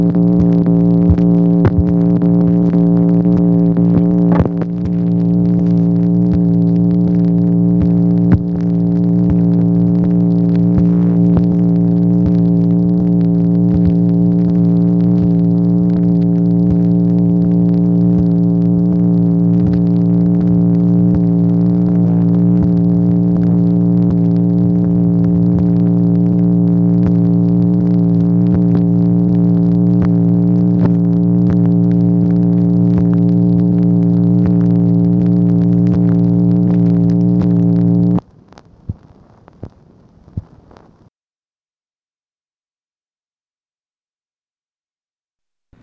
MACHINE NOISE, July 27, 1964
Secret White House Tapes | Lyndon B. Johnson Presidency